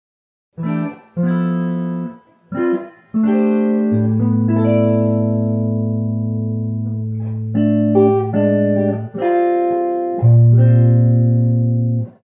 Tra l'altro è proprio su questo genere di considerazioni che si forma la cosiddetta "armonia quartale", in cui si usano accordi formati da intervalli interni di quarta (5 semitoni) o di quarta diesis, sia in modo diatonico (all'interno delle note di una tonalità), sia in modo atonale [